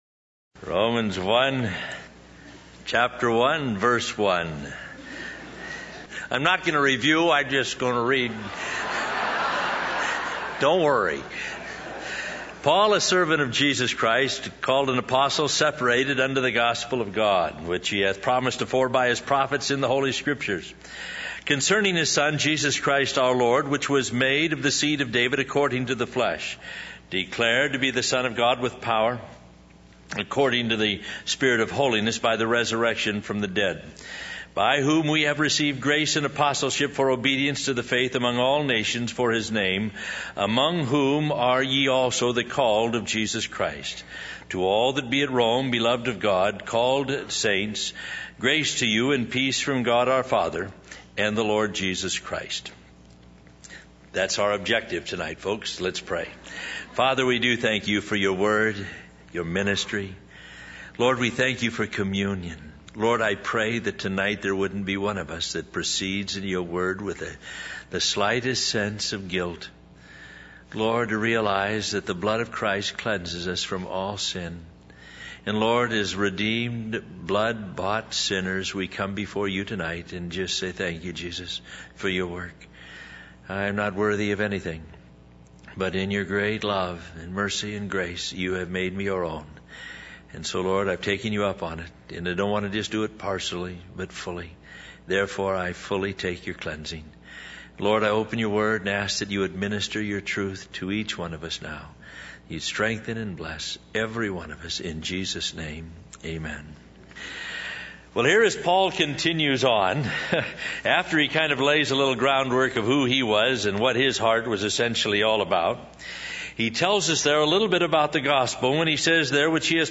In this sermon, the preacher discusses the events leading up to Jesus' crucifixion and resurrection. He highlights how Jesus was betrayed by a close friend for 30 pieces of silver, accused by false witnesses, and mocked and struck.